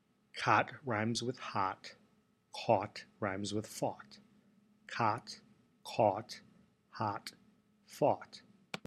In my version of english, cot sounds like bother and caught sounds like court.
this audio sample of me pronouncing cot/hot and caught/fought for elucidation.
cotcaught.mp3